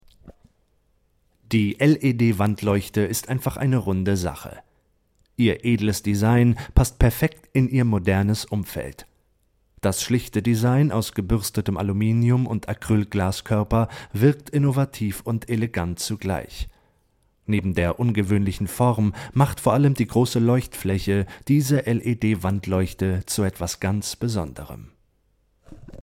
Frische, dynamische, flexible und markante Sprech- und Gesangsstimme, optimal für Werbung, Voice Over, Synchron, Dokumentationen, Hörbücher und Hörspiele.
Sprechprobe: Industrie (Muttersprache):
My voice is fresh, dynamic, warm, flexible and striking.